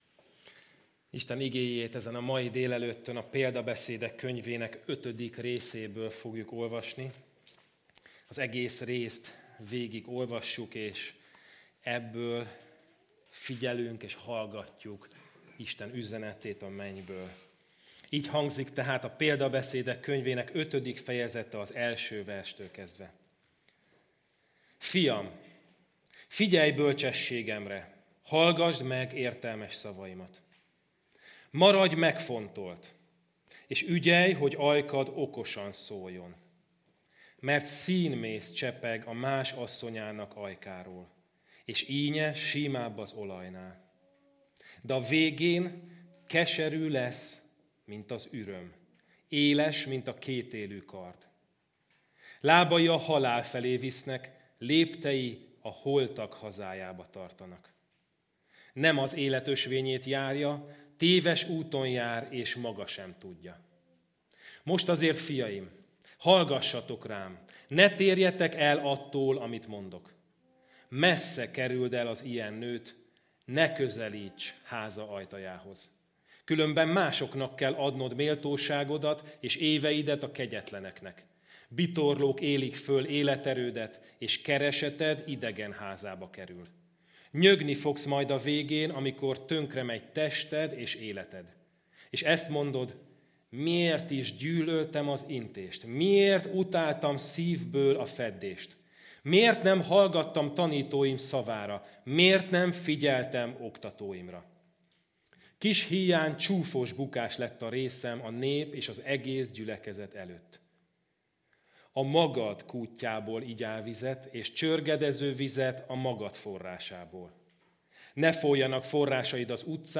 Kategória: Igehirdetés  Like  Tweet  +1  Pin it